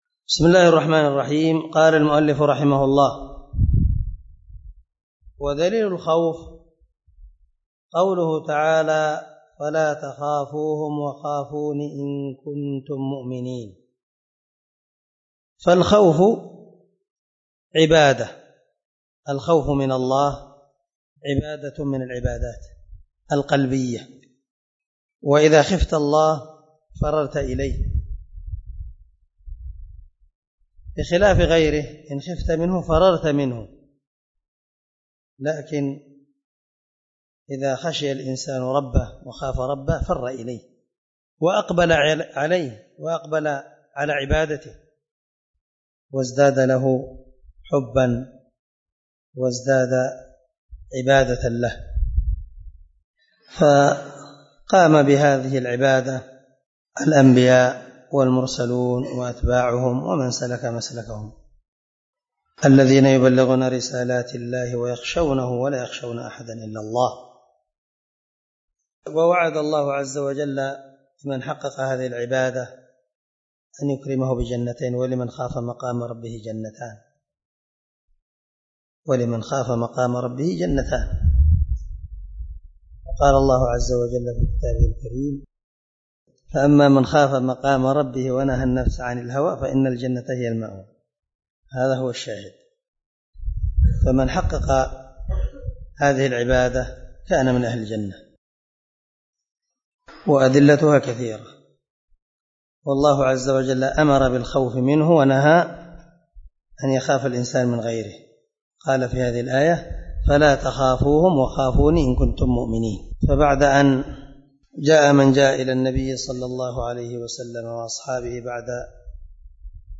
🔊 الدرس 13 من شرح الأصول الثلاثة